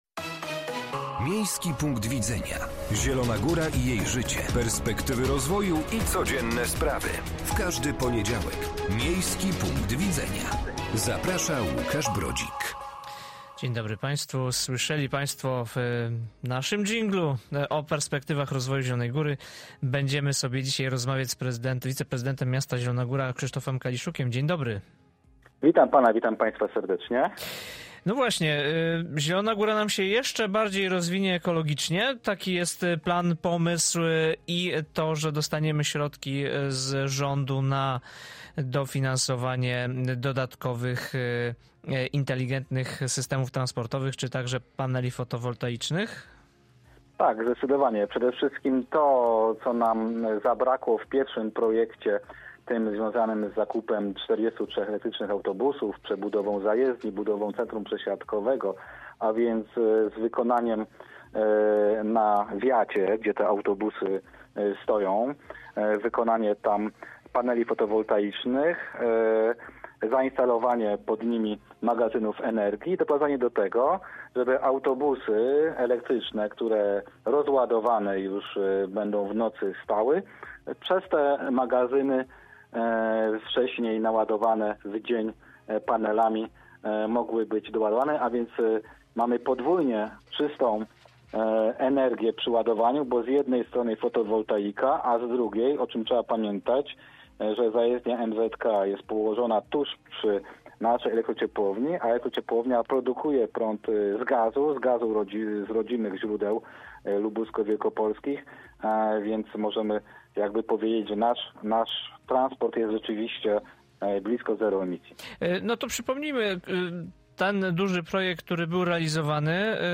Gościem audycji był Krzysztof Kaliszuk, wiceprezydent miasta Zielona Góra